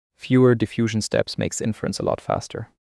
AI, TTS